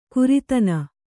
♪ kuritana